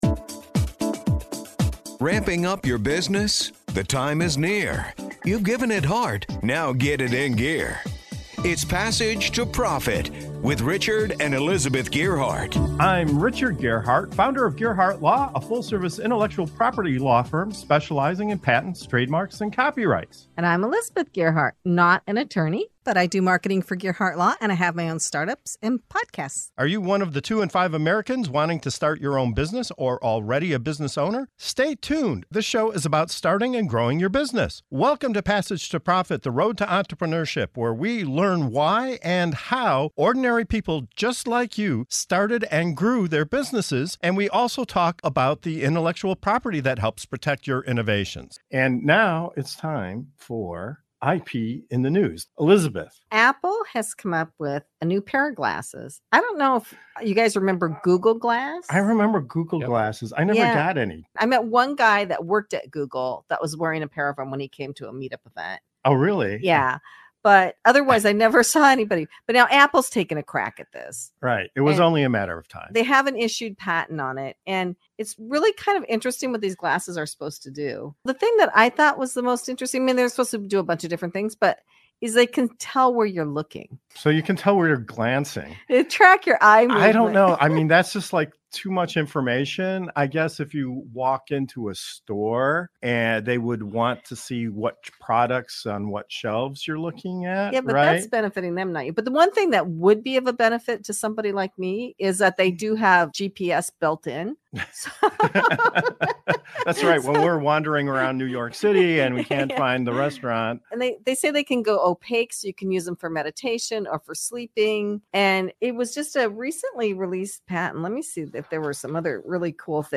We explore Apple's game-changing patent for smart glasses, delving into how augmented reality, gaze tracking, and GPS integration are set to redefine our digital landscape.